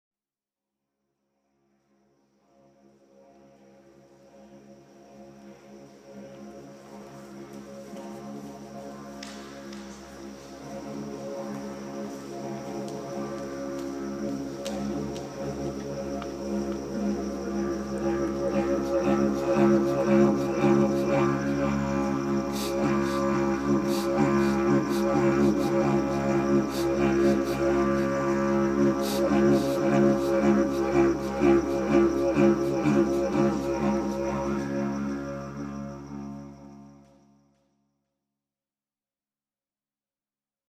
- exhaust pipe didgeridoo - constructed by Gunter from found elements
impromptu playing
exhaust pipe didge at the closing, from within the installation